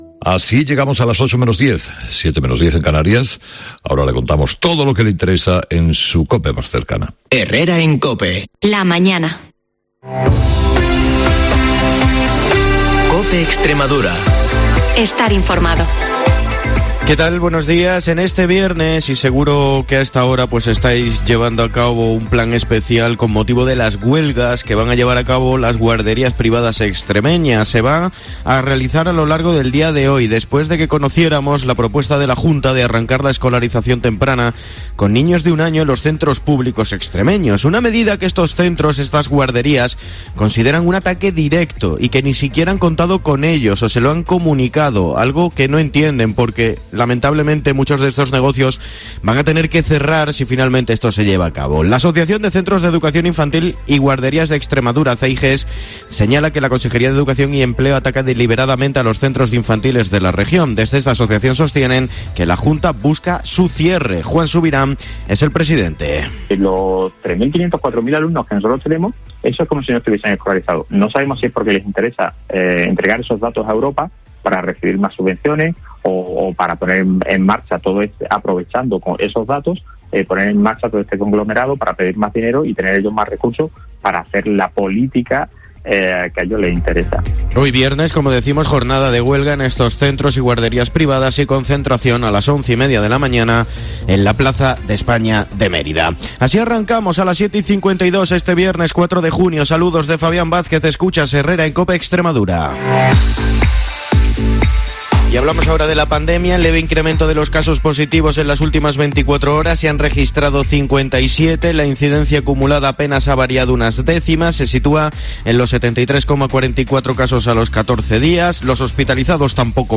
el informativo líder de la radio en la región